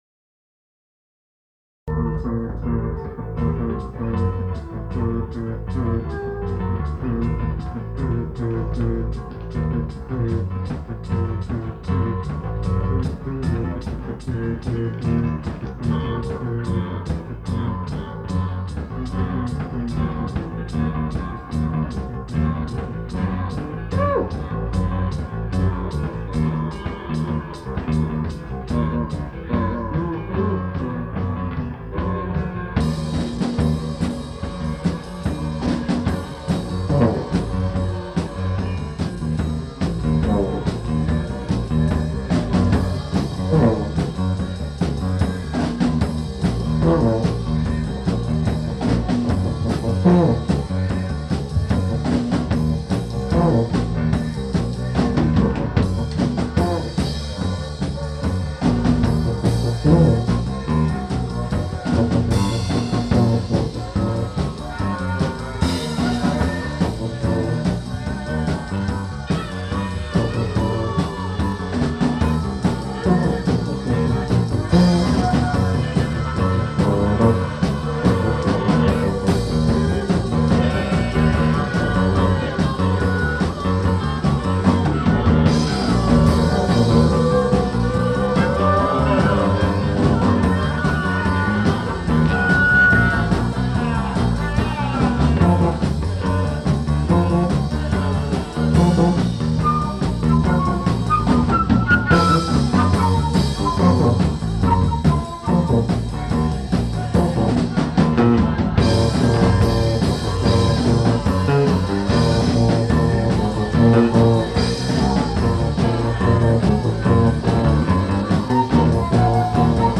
e-bass
guitar
voc, perc.
perc., sax, flute
tuba, microsynth
drums, perc.